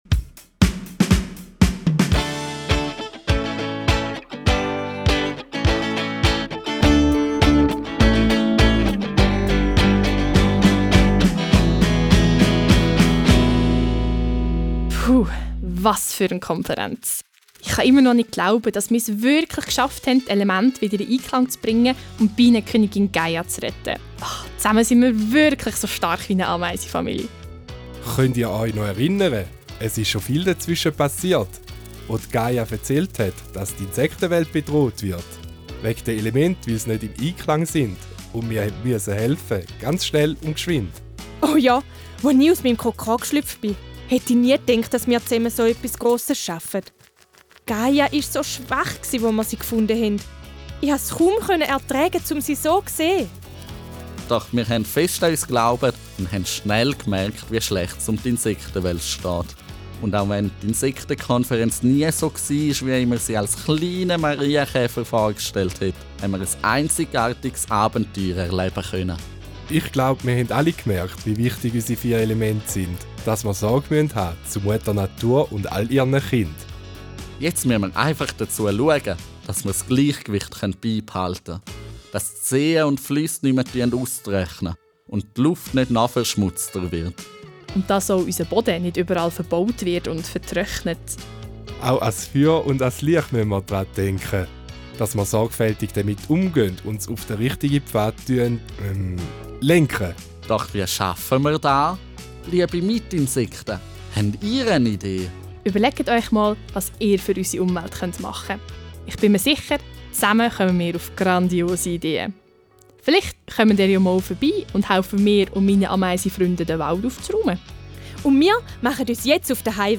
Unten findet ihr ein letztes Hörspiel.